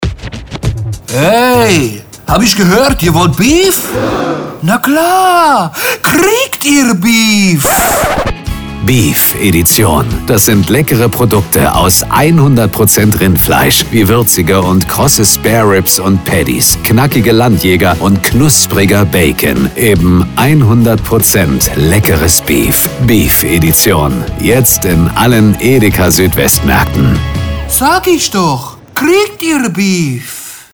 Die passenden Worte für den Beef Werbespot spricht unsere Werbestimme Dietmar Wunder.
Radiowerbung: Und so klingt das Ganze dann im Radio: